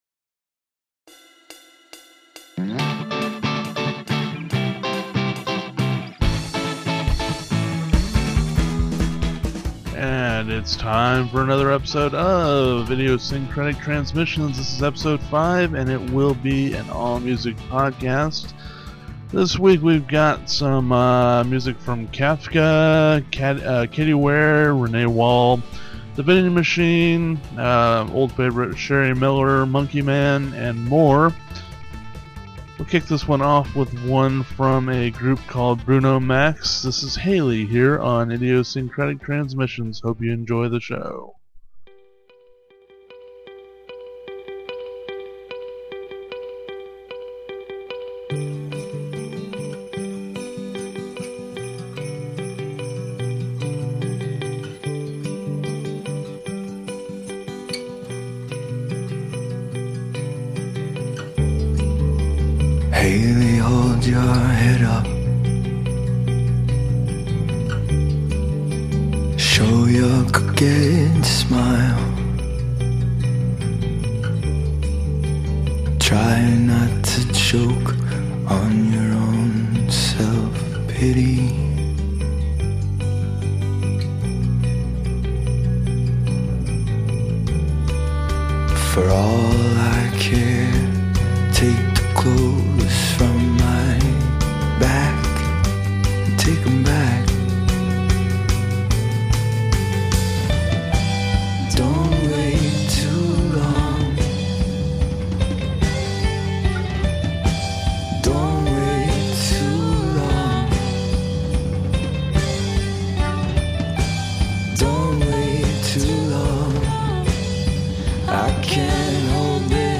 All music episode.